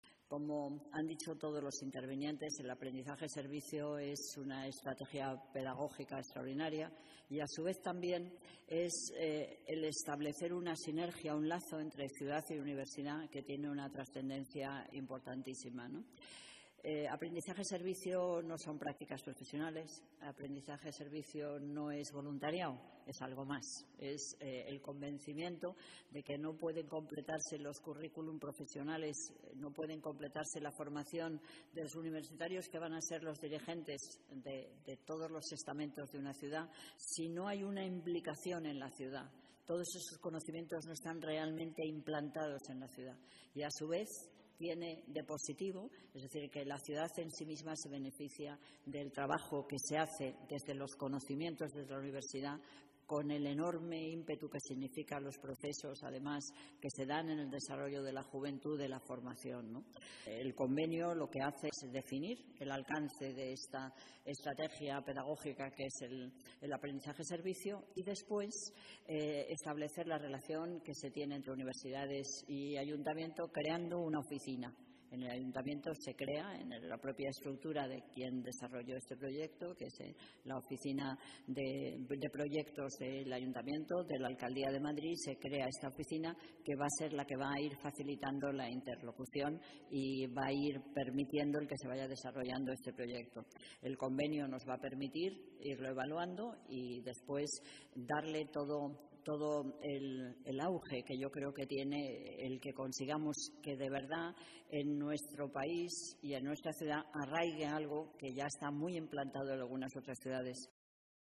Nueva ventana:Manuela Carmena habla sobre la estrategia pedagógica que supone este programa